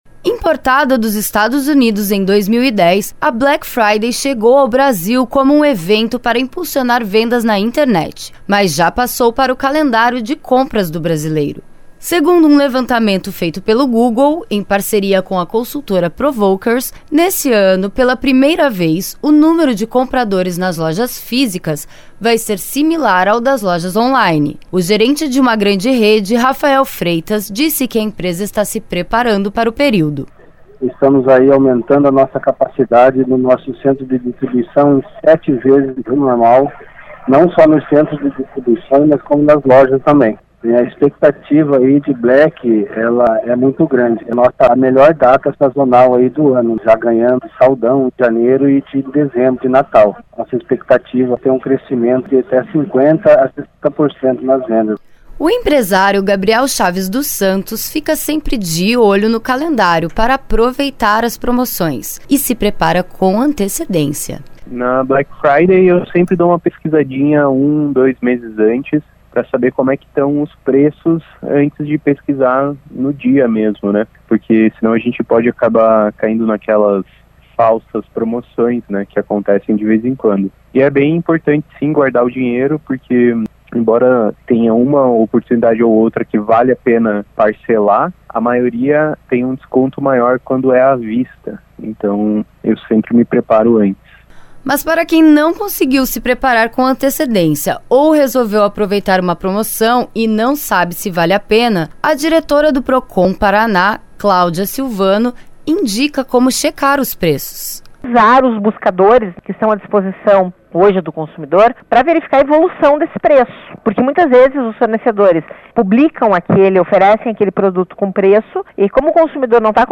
Mas para quem não conseguiu se preparar com antecedência ou resolveu aproveitar uma promoção e não sabe se vale a pena, a diretora do Procon-PR, Cláudia Silvano, indica como checar os preços